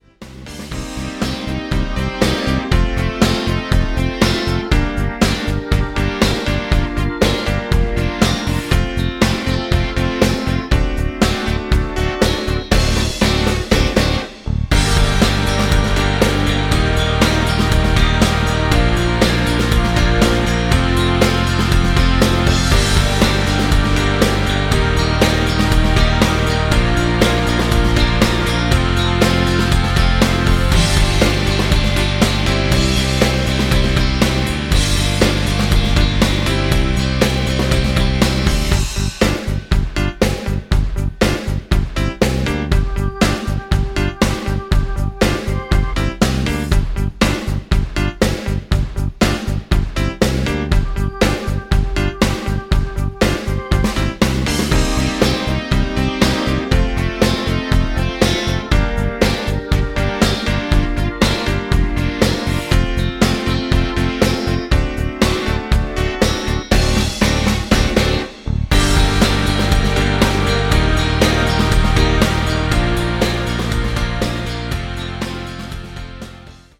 karaoke, strumentale